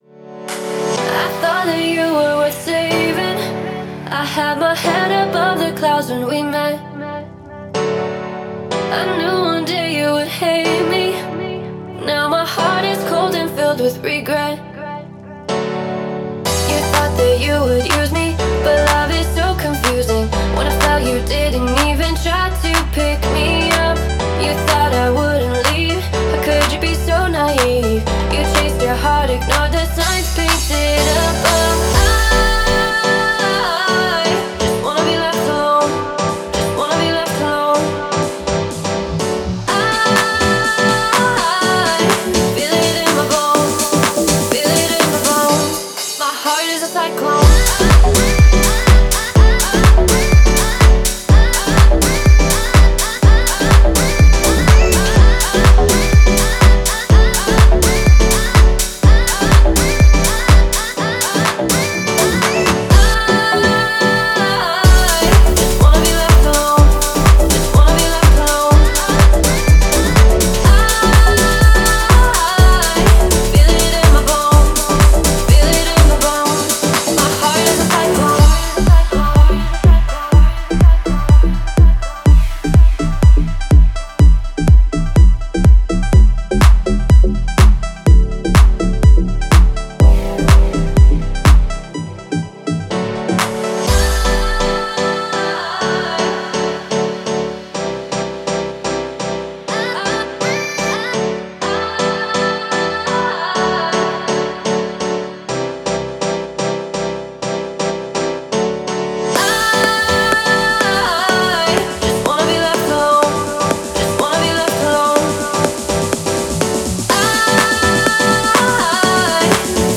это энергичная электронная композиция в жанре EDM